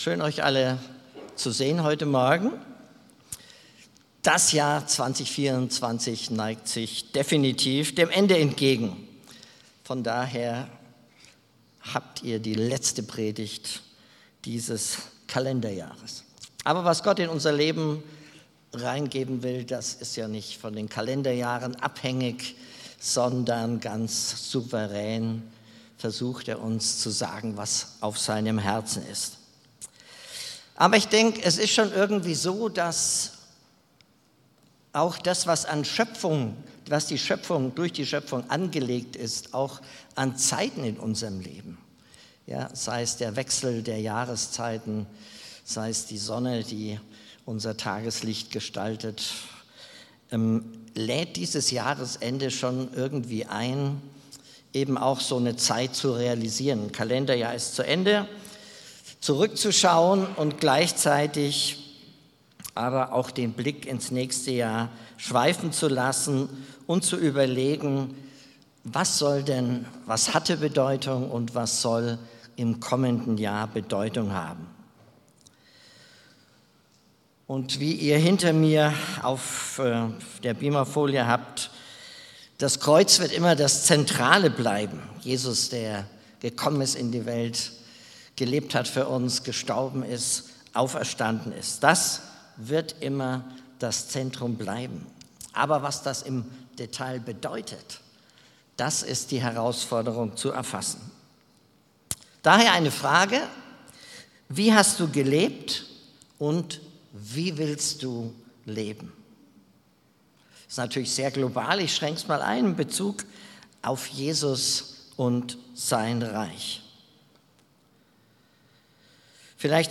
Jahresabschlussgottesdienst
Predigt